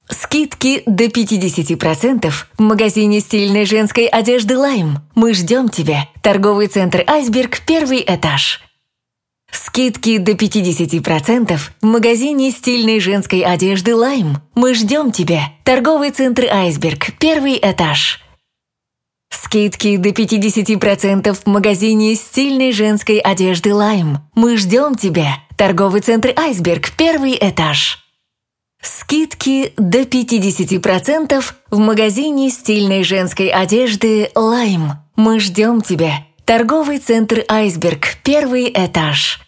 Профессиональный диктор для озвучки текста, рекламы